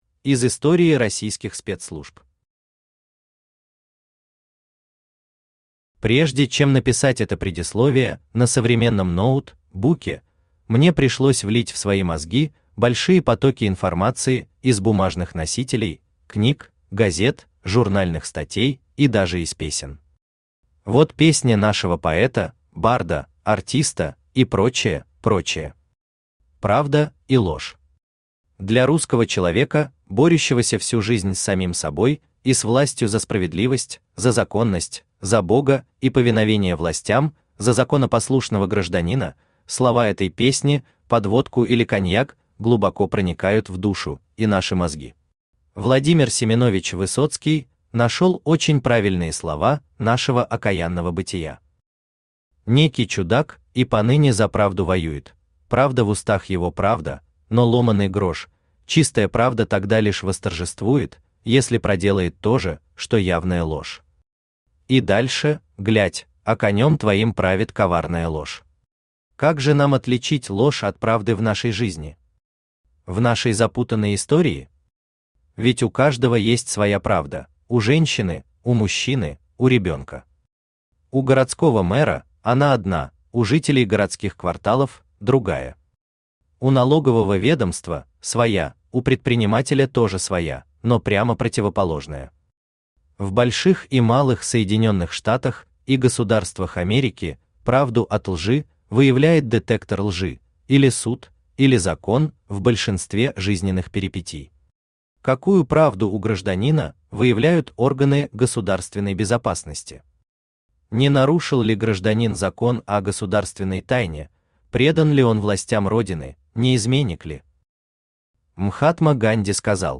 Аудиокнига НКВД | Библиотека аудиокниг
Aудиокнига НКВД Автор Вадим Гринёв Читает аудиокнигу Авточтец ЛитРес.